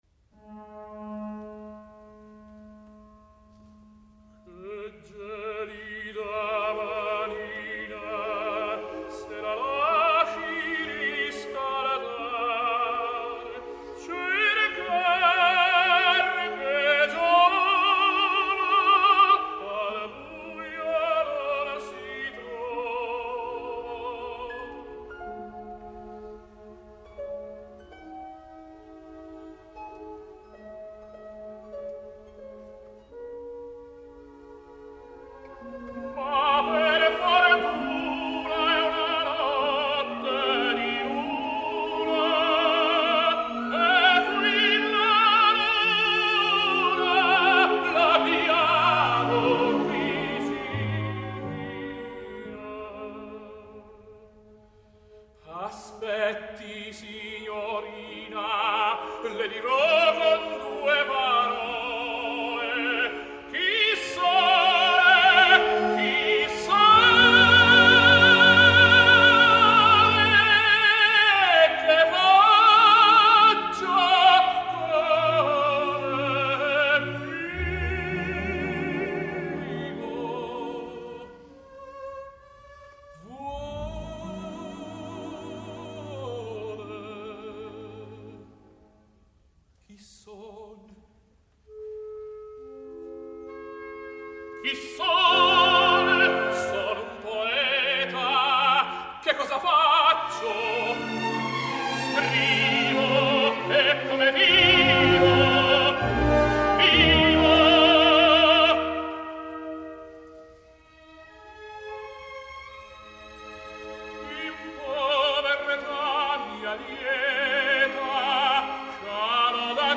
Classical, Opera